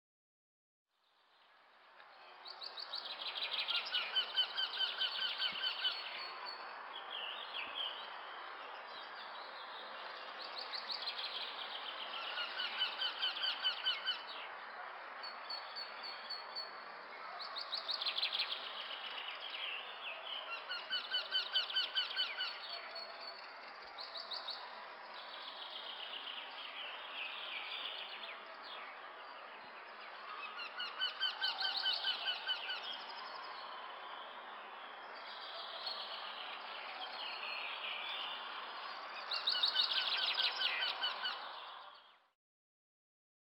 Käenpiian kiikityksessä on tunnusomainen valittava sävy, ja sarja on 12-18 tavuinen: kie-kie-kie-kie-kie-kie-kie-kie-kie-kie-kie-kie… Ääni voi joskus kuulostaa hieman käheältä. Sarjan alussa on pieni nousu, varsinkin ensimmäinen tavu erottuu muista.
Käenpiika